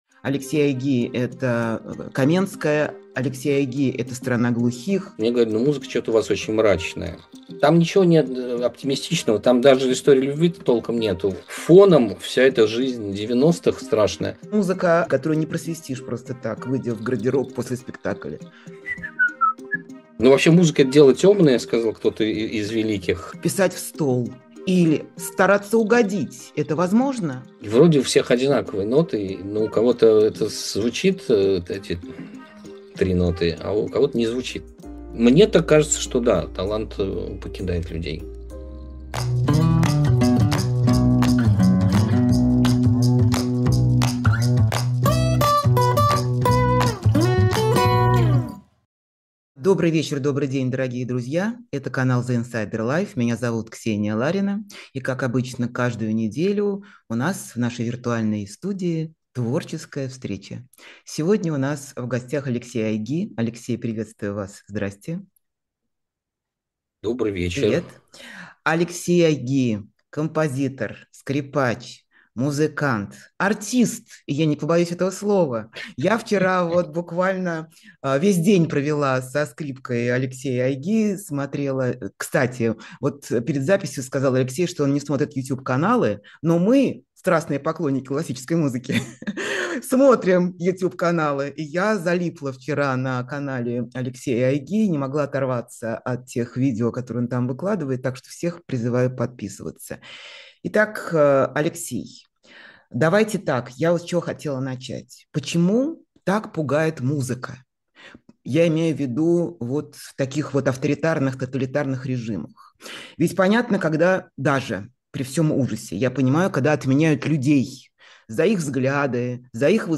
Эфир ведёт Ксения Ларина
Композитор, скрипач и автор музыки к кино и театру Алексей Айги — новый гость программы «Честно говоря» с Ксенией Лариной. Почему музыка пугает авторитарные режимы? Как в ней работает цензура?